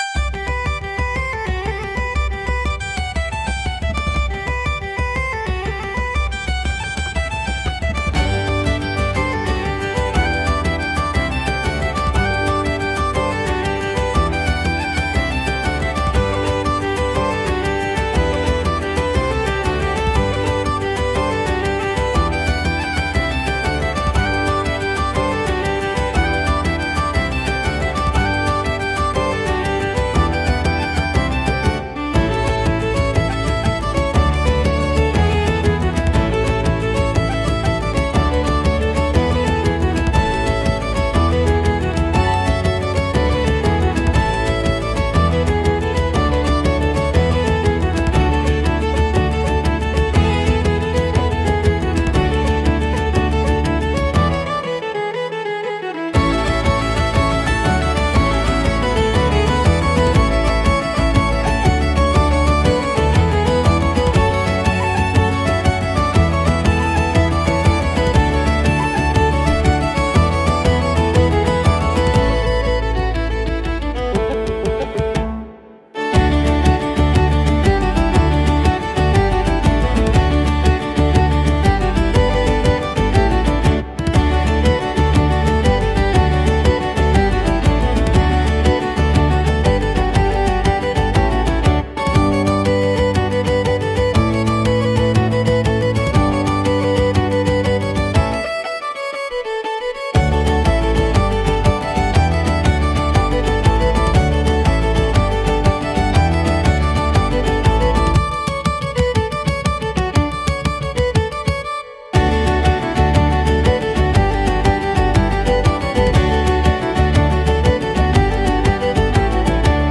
神秘的で豊かな民族色があり、物語性や異国情緒を演出するシーンに効果的なジャンルです。